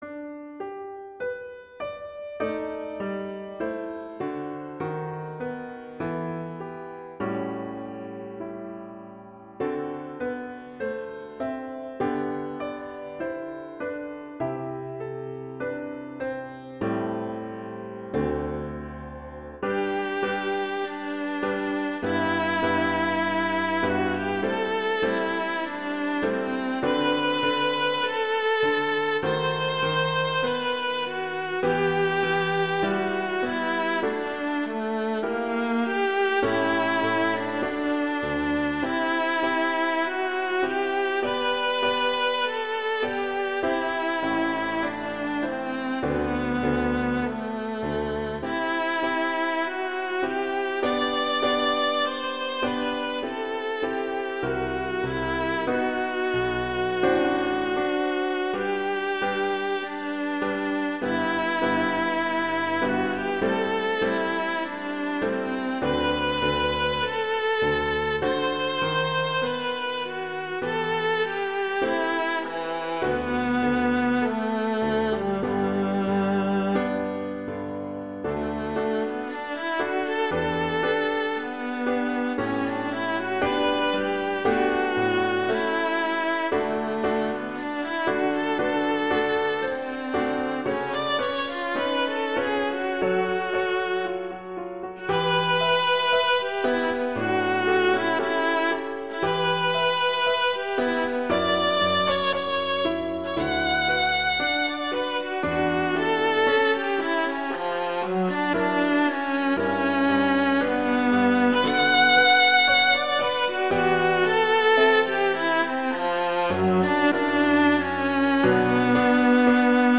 classical, christian, hymn
G major
♩=100 BPM